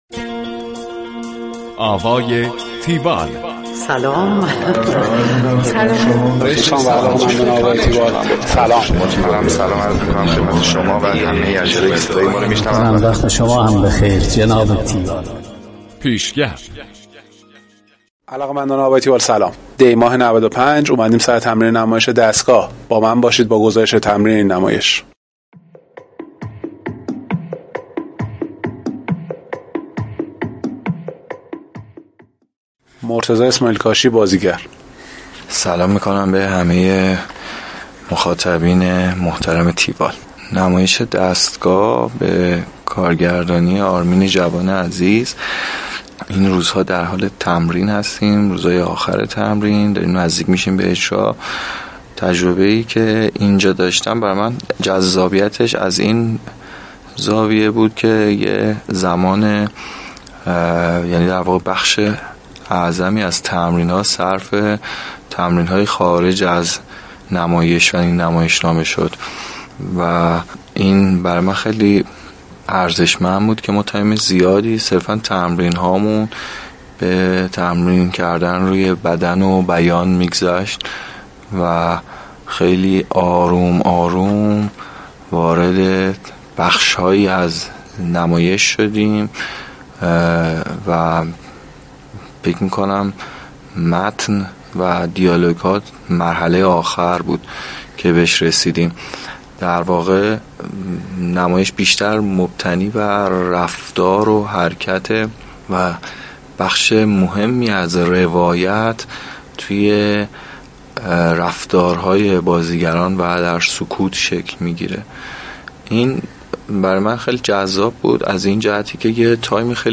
گزارش آوای تیوال از نمایش دستگاه